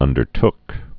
(ŭndər-tk)